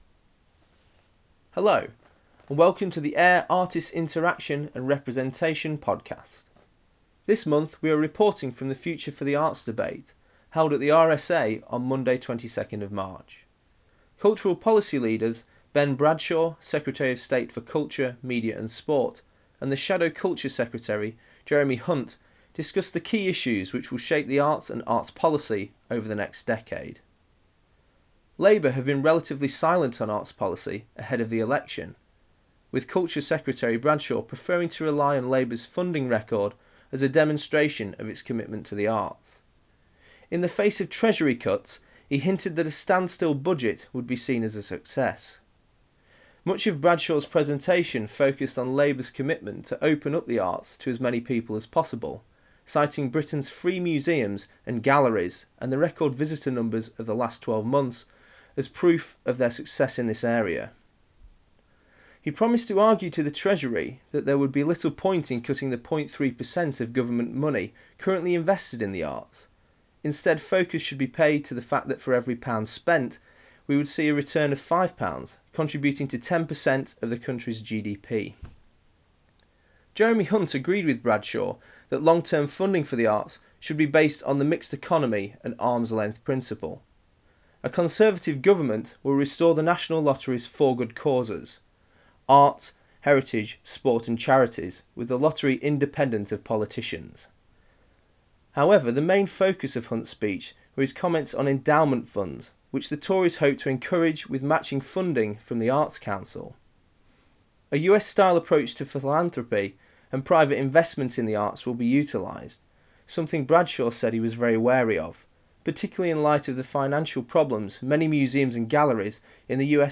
AIR attended The Future for the Arts at the RSA on March 22nd 2010. Culture policy leaders Ben Bradshaw MP, Secretary of State for Culture, Media and Sport and Jeremy Hunt MP, Shadow Secretary debated the key issues which will shape arts policy and management over the next decade.